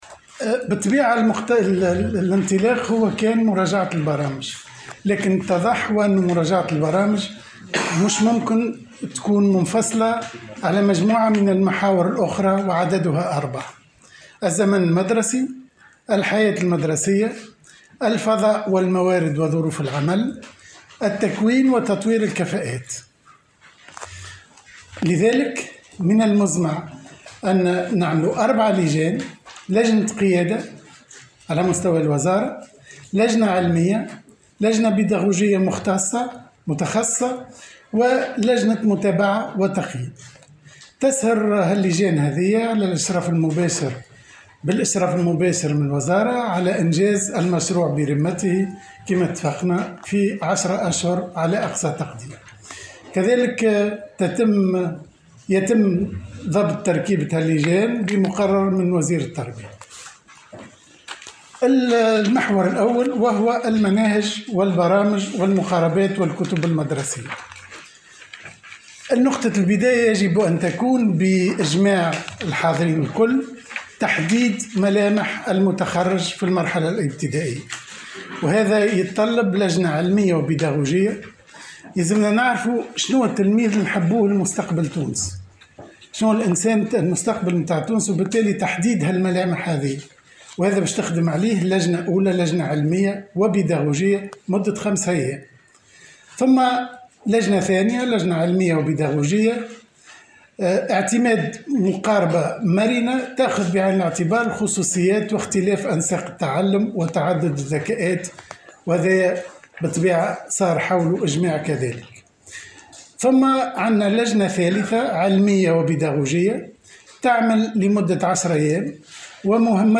قال وزير التربية فتحي السلاوتي، خلال ندوة صحفية عقدها اليوم الاربعاء، حول مراجعة البرامج وتطويرها في قطاع التعليم الابتدائي، انه تم الاتفاق مع الجامعة العامة للتعليم الأساسي والنقابة العامة لمتفقدي المدارس الابتدائية على مراجعة اربعة محاور رئيسية في تعلمات التلاميذ على رأسها الزمن المدرسي والحياة المدرسية للتلاميذ والتخفيف من البرامج.